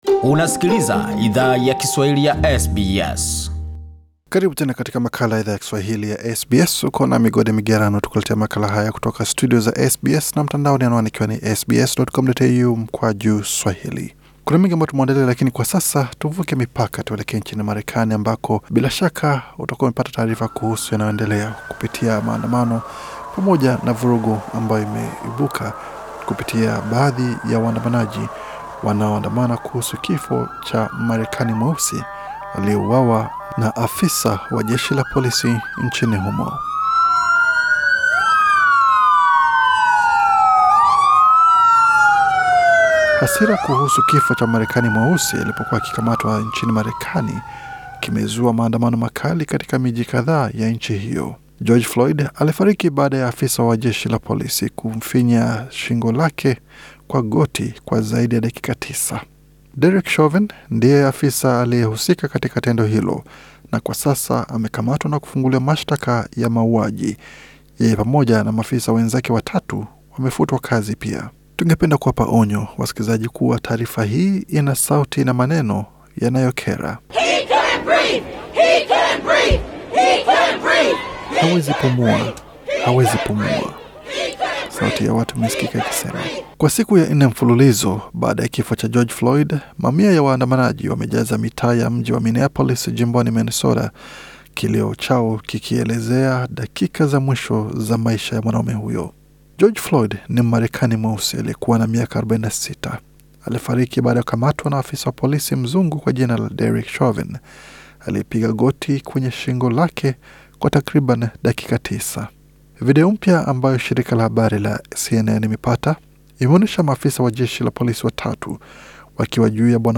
ONYO: Taarifa hii ina sauti na maneno yanayo khera Share